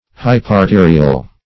Search Result for " hyparterial" : The Collaborative International Dictionary of English v.0.48: Hyparterial \Hy`par*te"ri*al\, a. [Hypo- + arterial.]